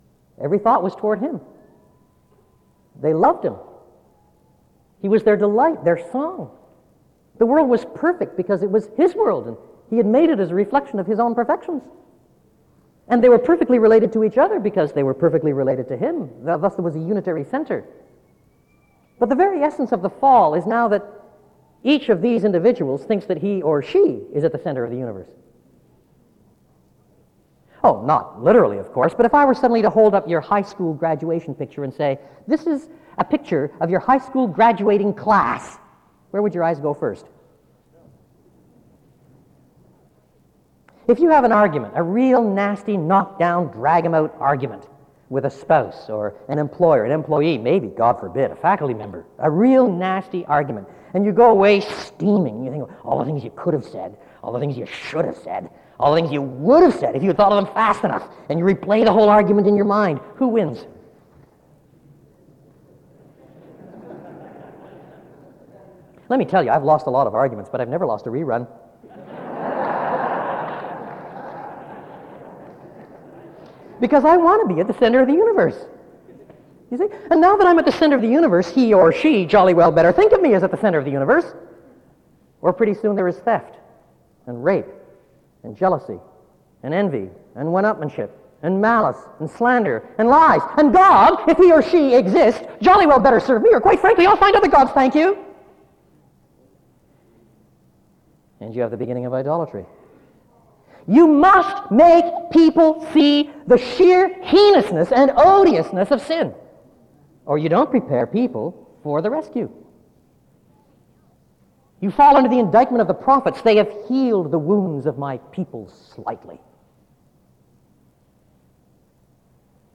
SEBTS_Chapel_Donald_A_Carson_2000-02-09_B.wav